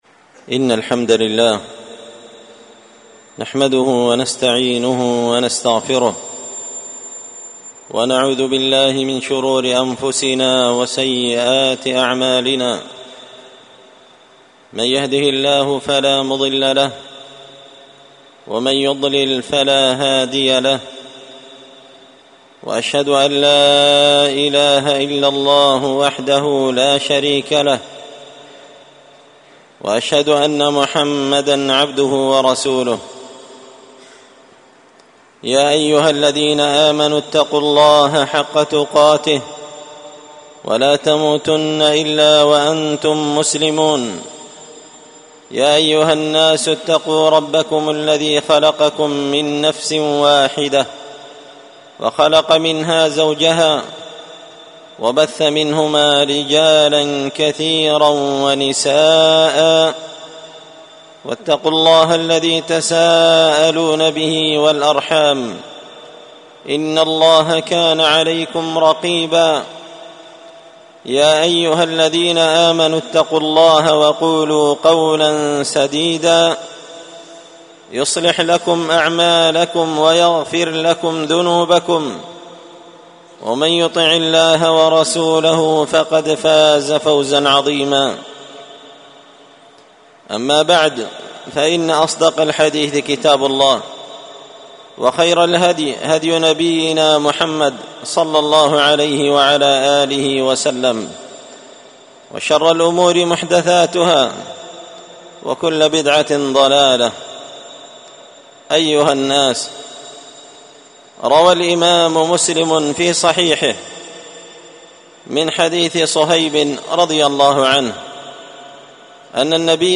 خطبة جمعة بعنوان:
ألقيت هذه الخطبة بدار الحـديـث السلفية بمـسجـد الفـرقـان قشن-المهرة-اليمن تحميل